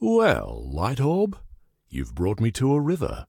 B_river2.ogg